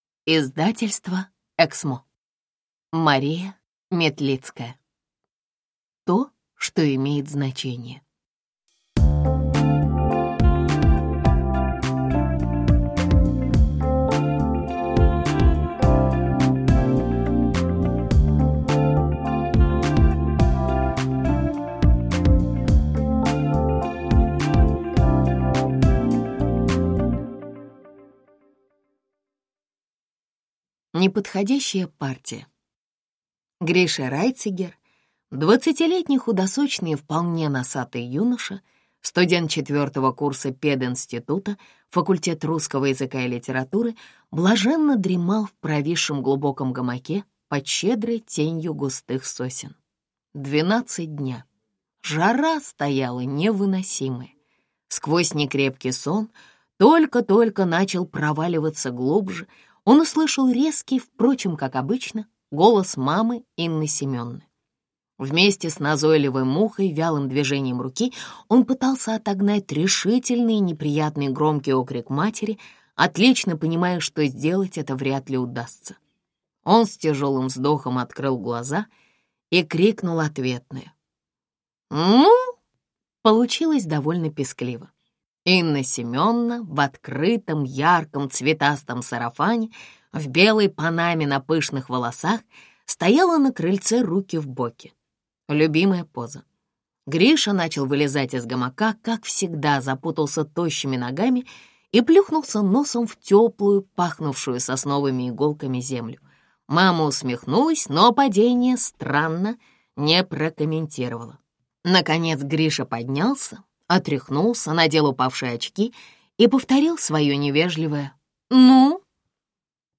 Аудиокнига То, что имеет значение | Библиотека аудиокниг